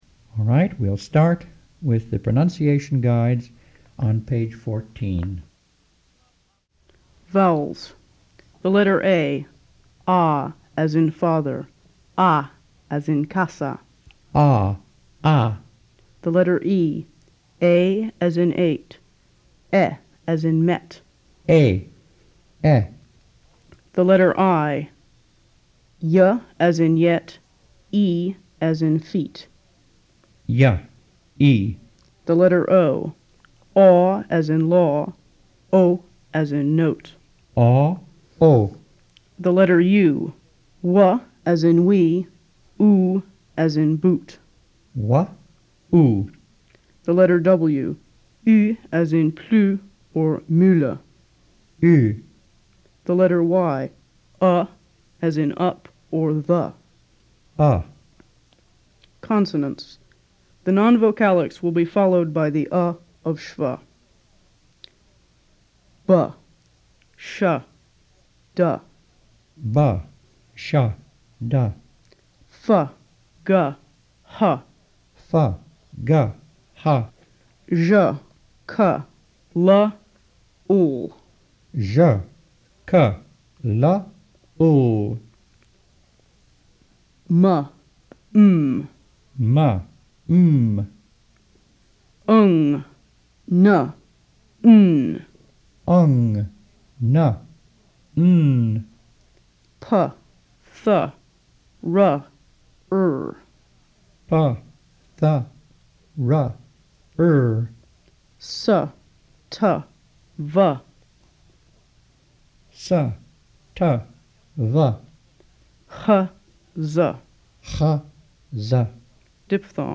PronunciationHQ.mp3